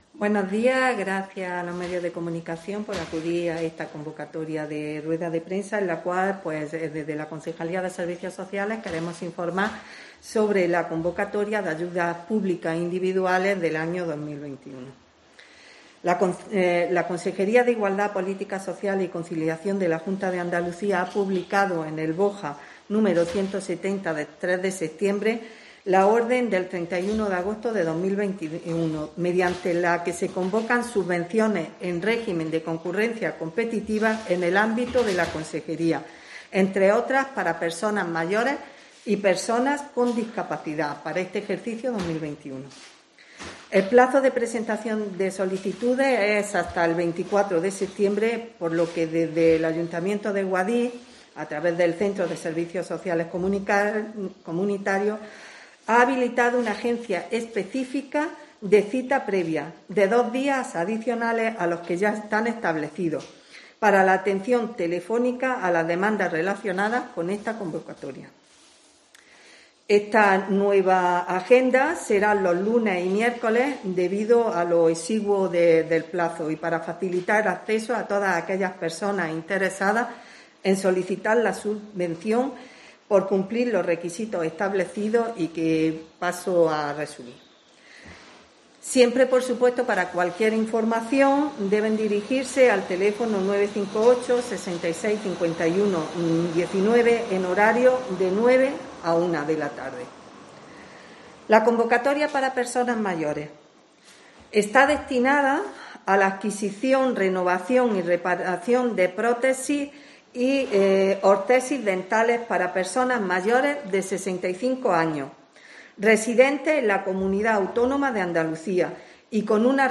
Rueda Prensa Concejala de Asuntos Sociales